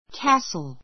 cas t le 小 A2 kǽsl キャ スる ｜ kɑ́ːsl カ ー スる （ ⦣ t は発音しない） 名詞 城 Edinburgh Castle Edinburgh Castle エジンバラ城 An Englishman's house is his castle.